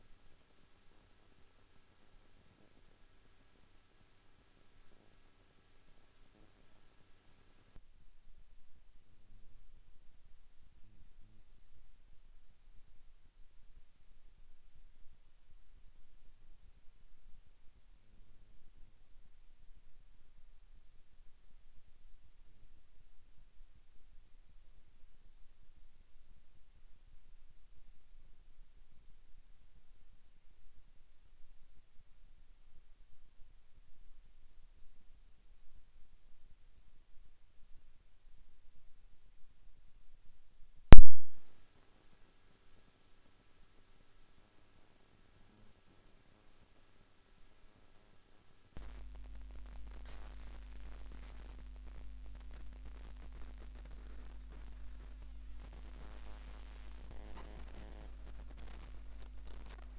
QUA - QUA -QUA".
quaquaqua.wav